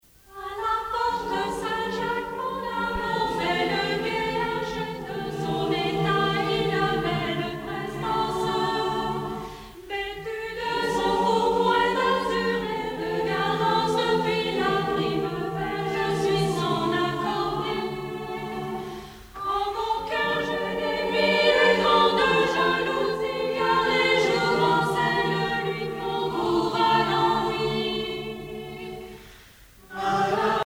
Chorales de France
Pièce musicale éditée